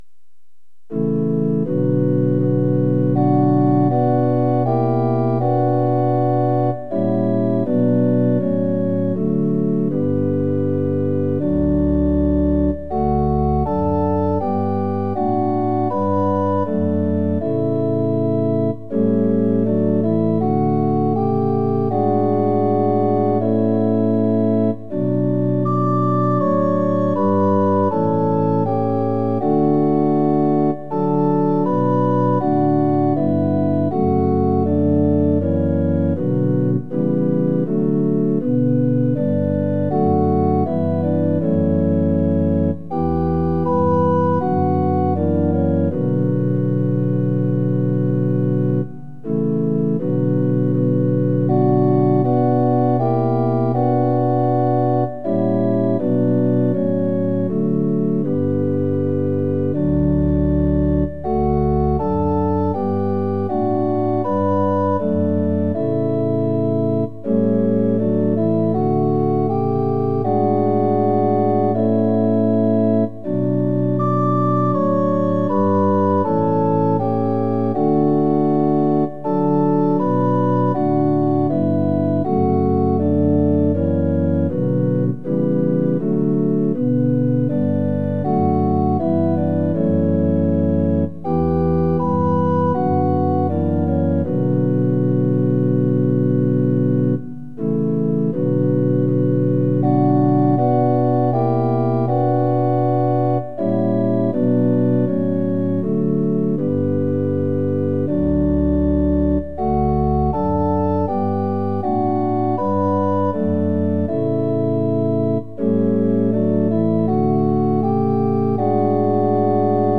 ◆　４分の４拍子：　一拍目から始まります。